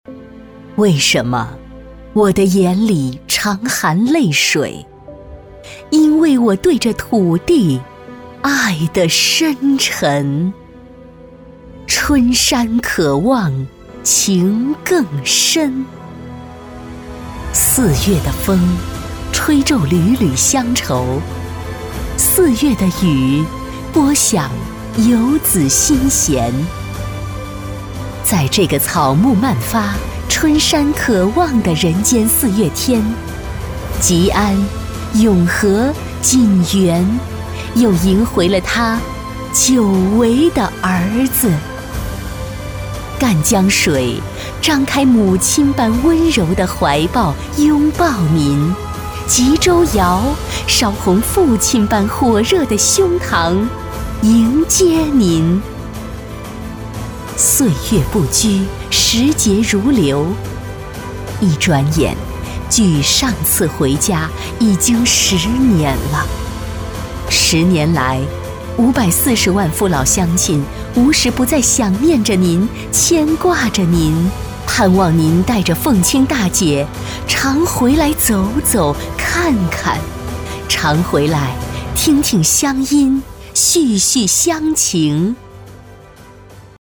女66-朗诵 故人回乡 深情
女66大气专题 v66
女66-朗诵--故人回乡--深情.mp3